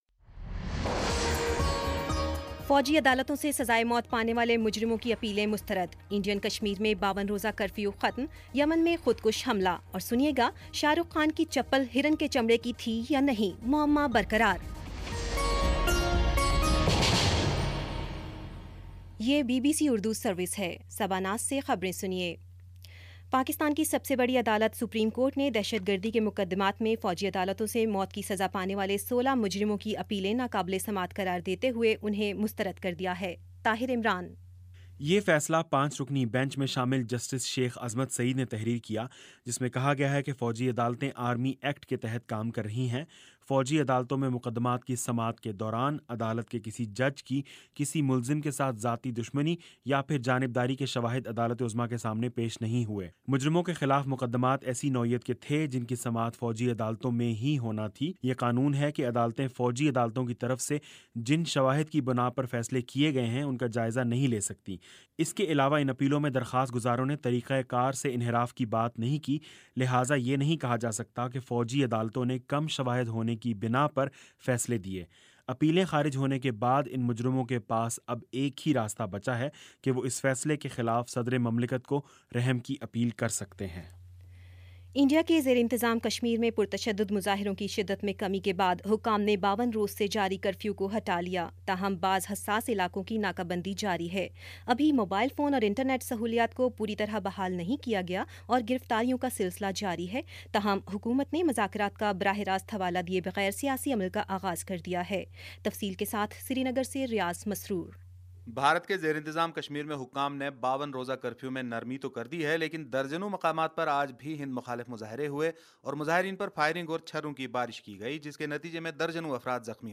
اگست 29 : شام پانچ بجے کا نیوز بُلیٹن